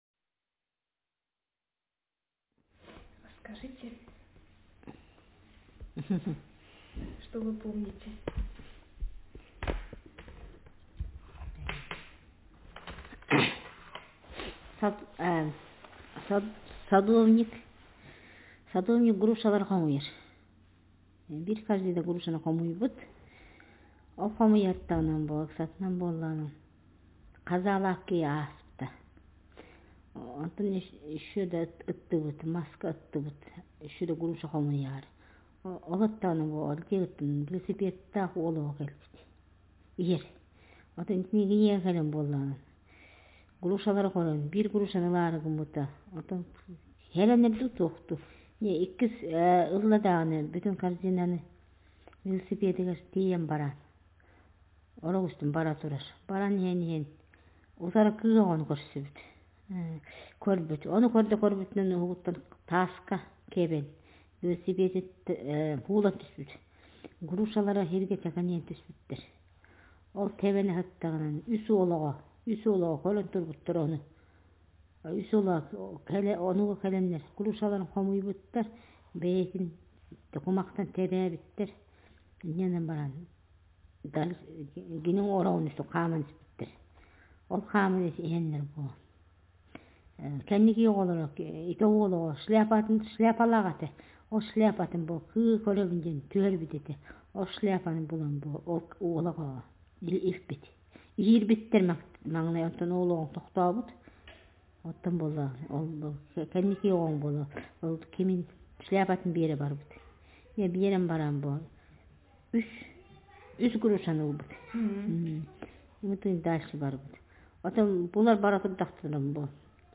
Speaker sexf
Text genrestimulus retelling